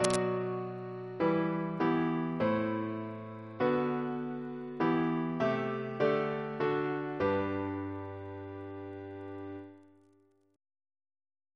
Single chant in G Composer: Lionel Dakers (1924-2003) Reference psalters: H1982: S427; RSCM: 172